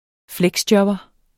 Udtale [ ˈflεgsˌdjʌbʌ ]